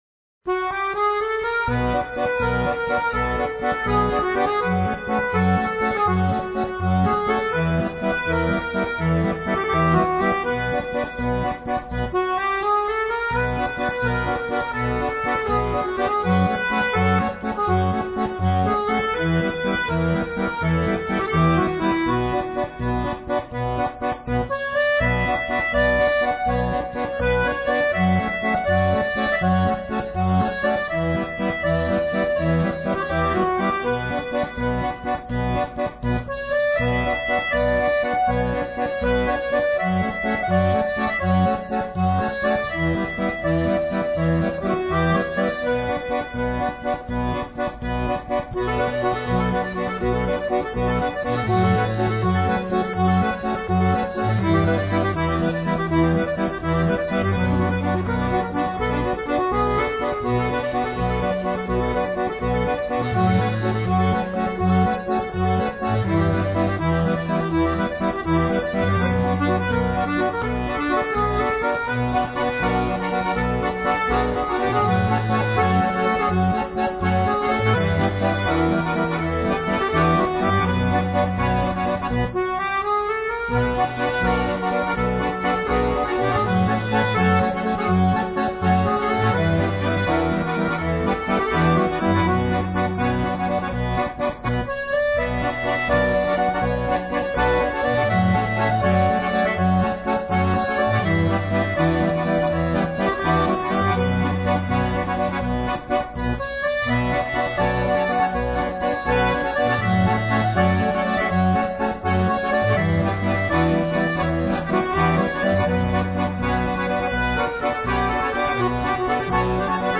Вальс